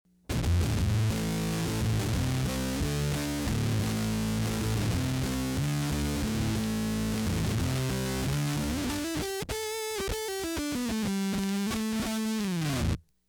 Samples were made using a Fender Stratocaster with Lollar S Series single coil pickups and a Fender Concert amp. Neck pickup selected with volume and tone controls at maximum. Standard tuning. The amp tone controls are set to 12 o’clock with the reverb turned off.